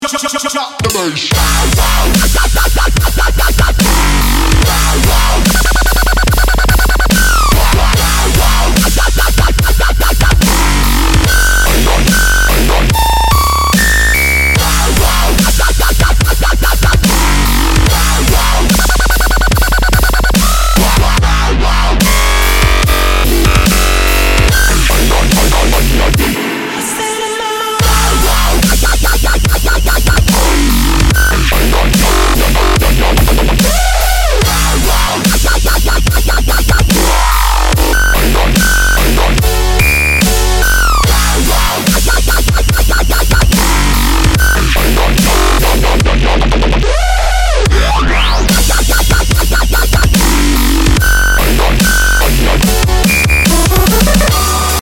• Качество: 128, Stereo
жесткие